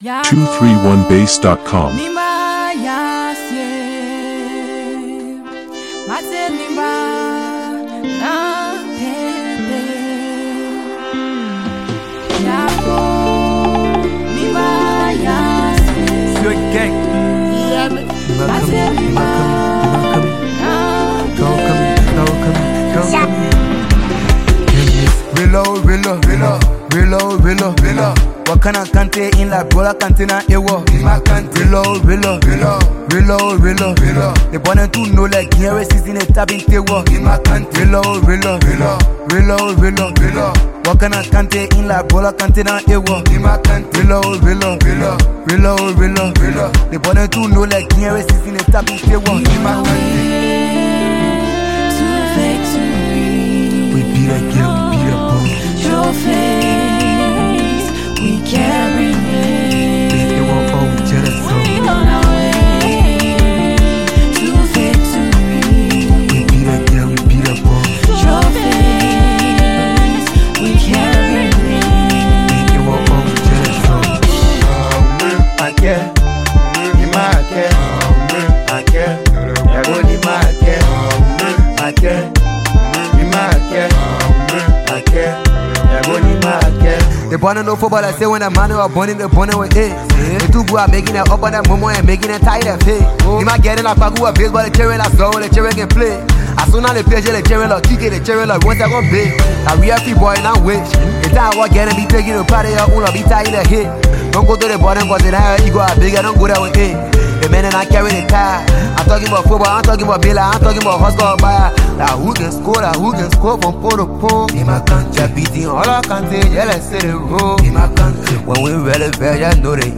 Get ready for the ultimate hype anthem for Nimba County!